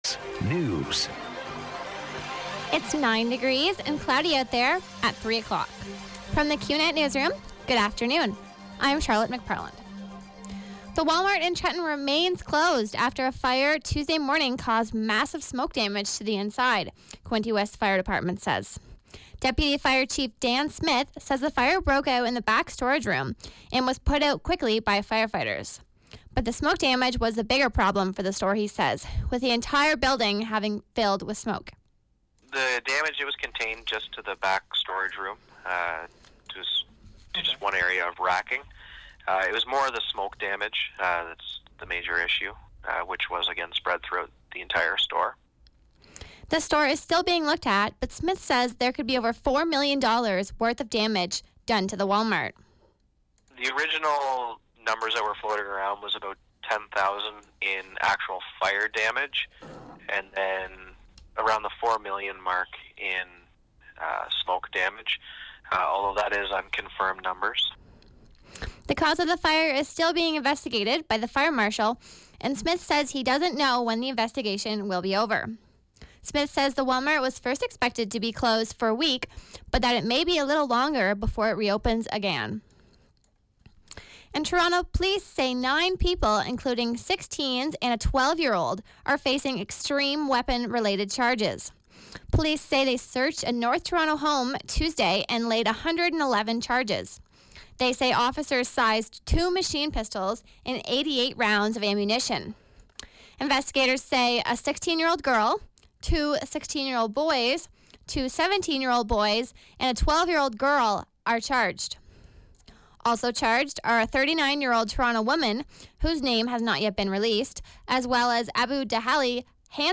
91X Newscast- Wednesday, Nov. 16, 2016, 3 p.m.